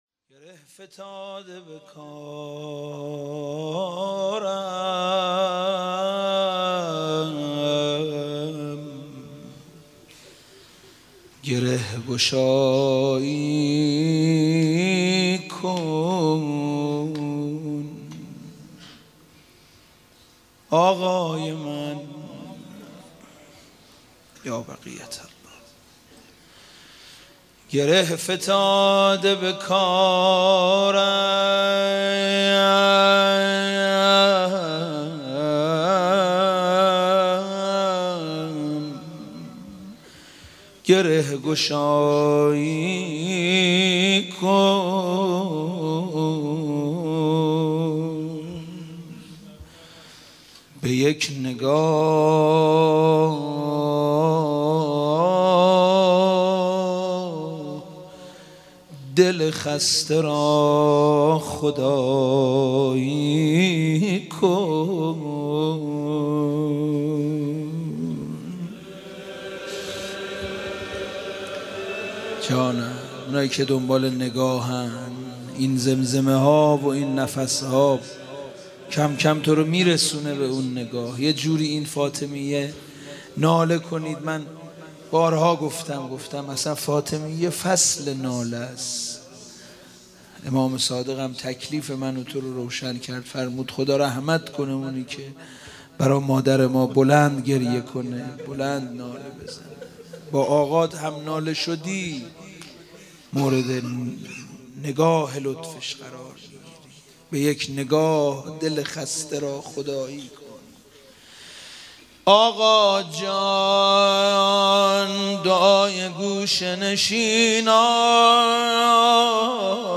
شب 1 فاطمیه 95 - هیئت یازهرا سلام الله علیها قم - روضه - گره فتاده به کارم